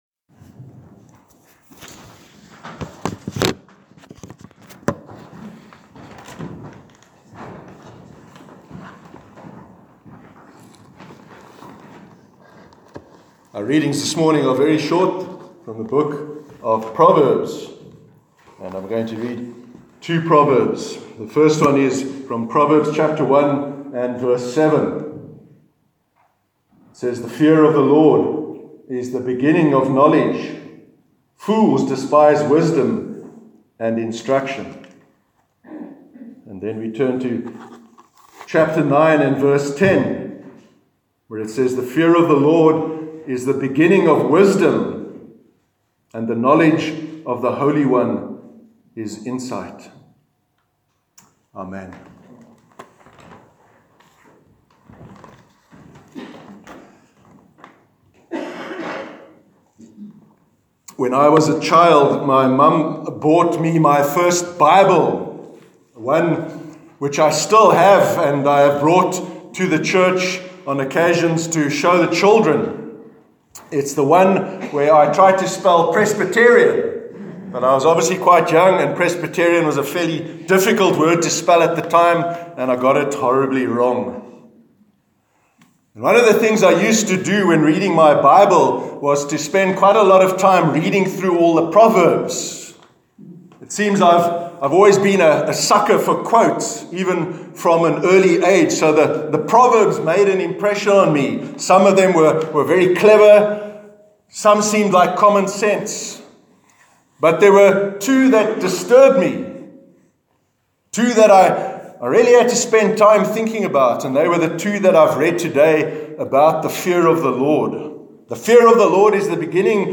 Sermon on the Fear of God- 23rd September 2018